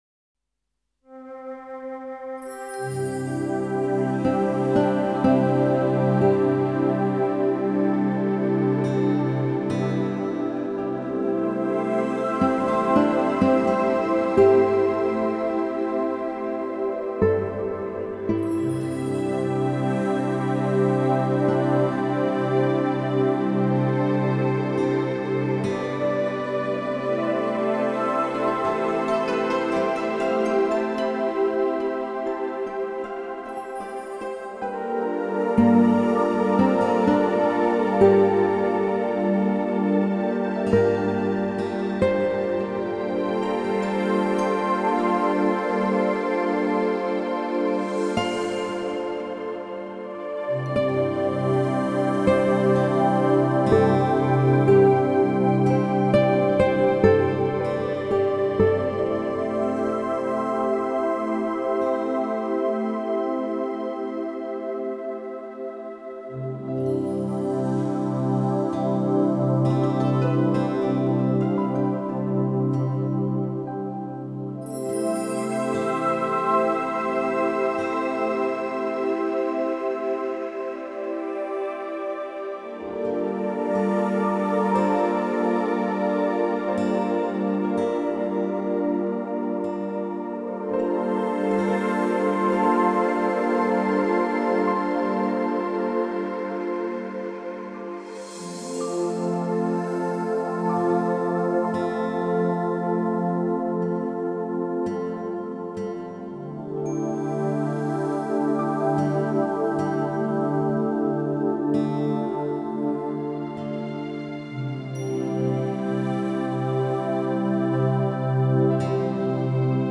Znova hudba vhodná pre reiki, relaxáciu a meditáciu.